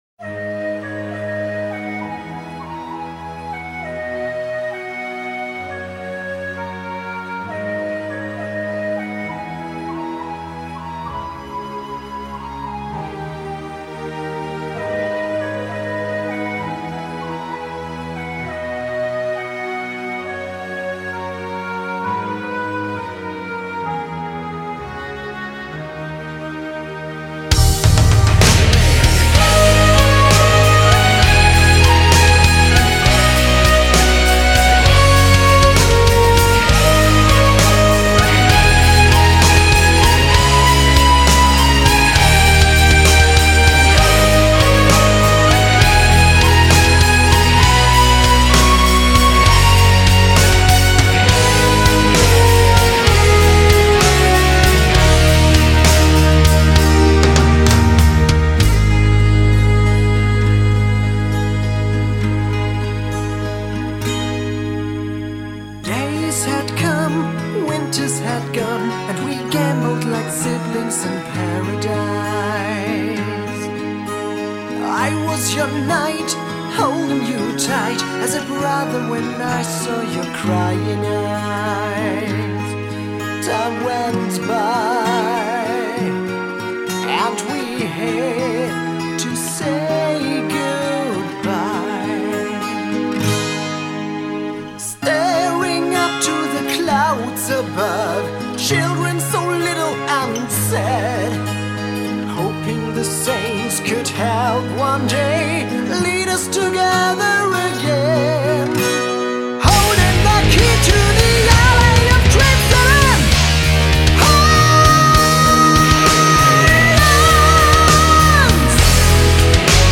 荡气回肠